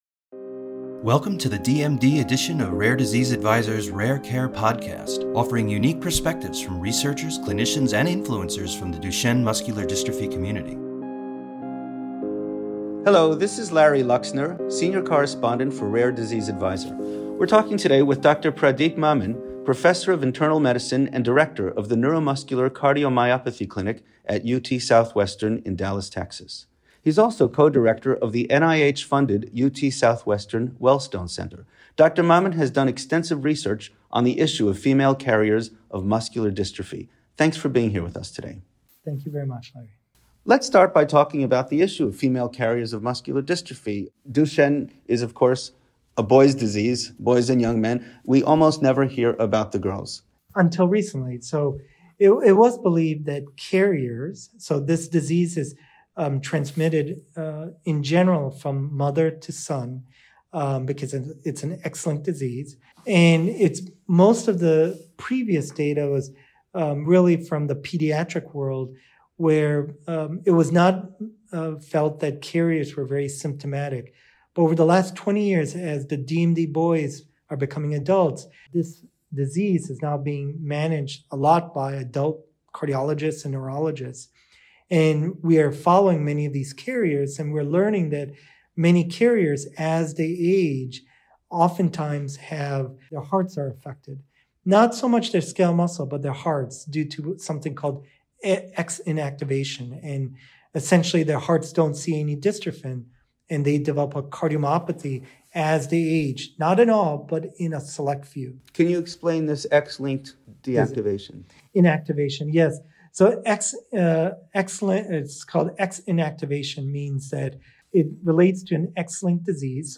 Rare Care Podcast / An interview